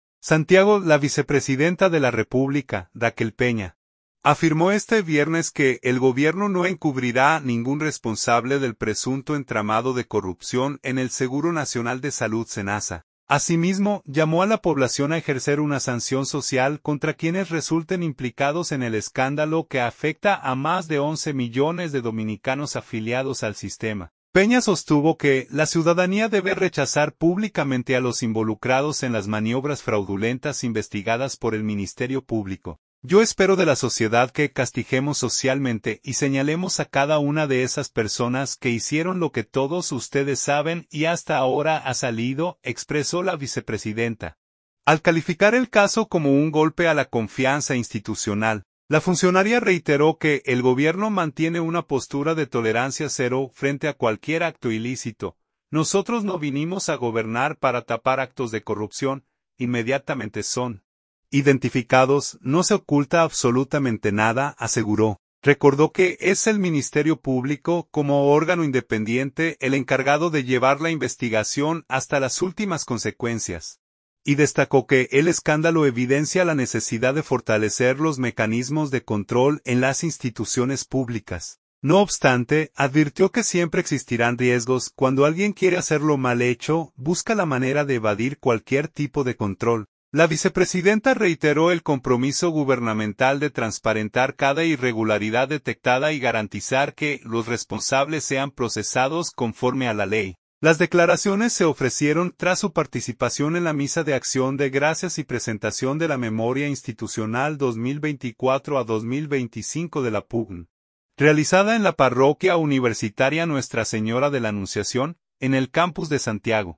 Las declaraciones se ofrecieron tras su participación en la misa de acción de gracias y presentación de la memoria institucional 2024-2025 de la PUCMM, realizada en la parroquia universitaria Nuestra Señora de la Anunciación, en el campus de Santiago.